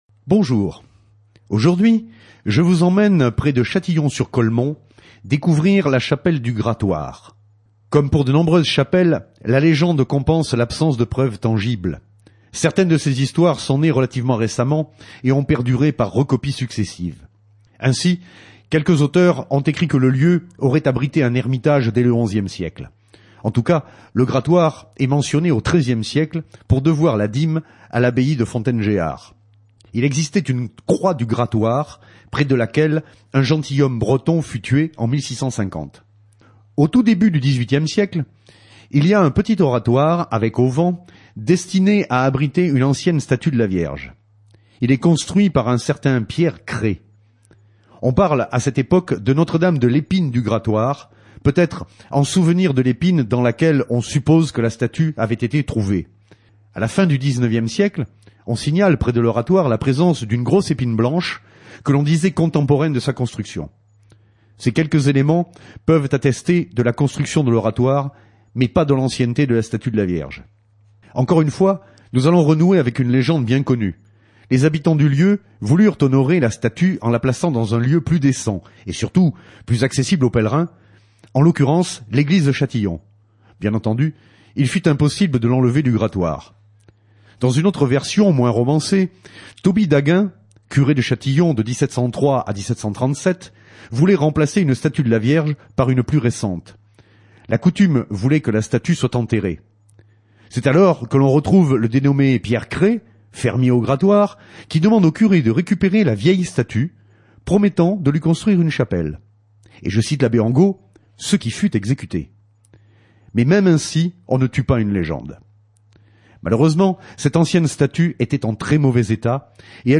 La S.AH.M. sur Radio Fidélité